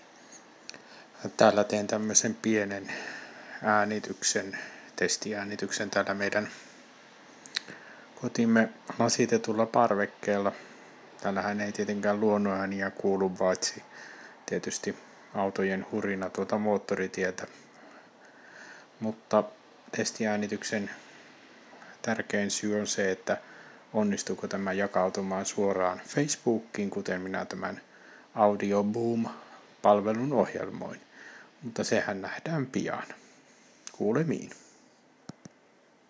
Vähän ääntä parvekkeelta
Testasin, että toimiiko tämän parvekkeella tehdyn äänitteen jako suoraan Facebookkiin.